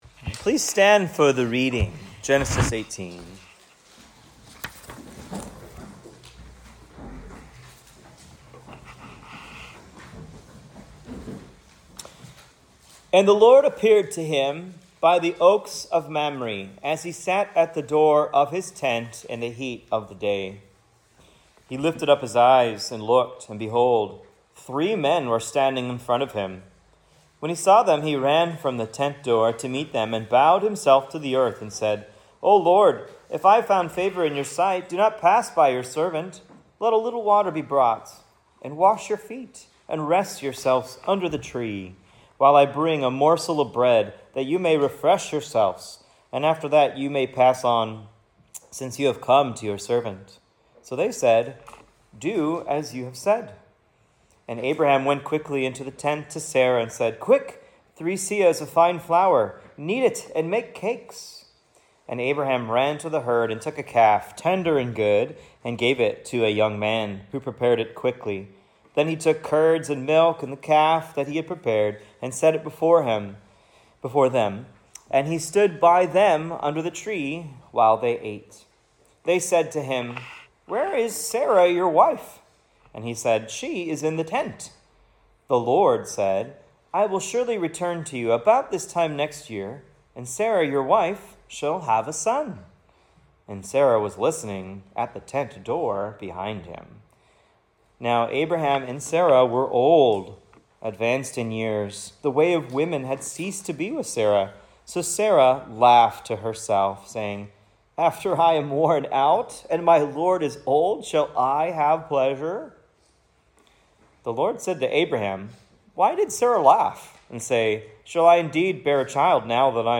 Here's the notes and audio for a sermon on Genesis 18 I preached at Cross of Christ Fellowship in Naperville: "Judge of all the Earth"